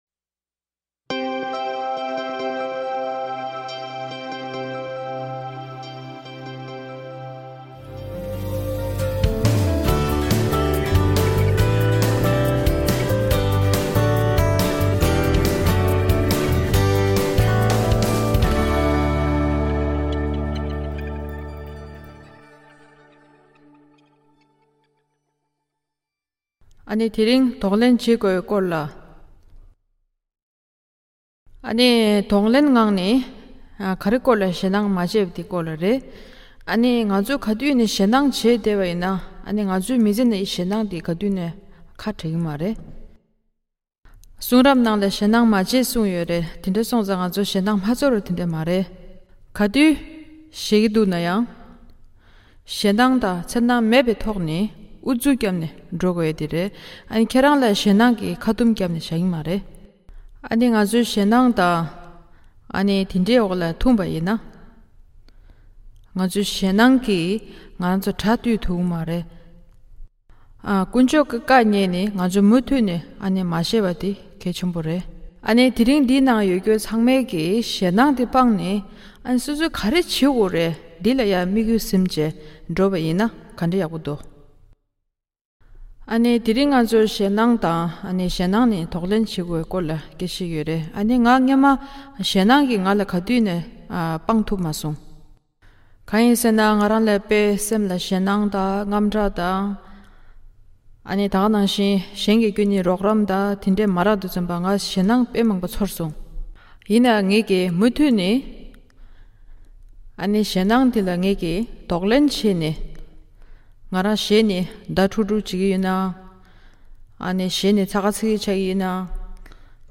རོམ་པ་པོ་གྲགས་ཅན་དང་ཡེ་ཤུའི་དམ་པའི་གསུང་རབས་ཀི་དགེ་རྒན་Joyce Meyerལ་ཉན་ནས་ཁྱབ་སེལ་དང་། གཞན་ལ་ཉིན་རེའི་འཚོ་བ་ཇེ་སིད་ཡོང་ཆེད་སྐུལ་མ་གཏོང་།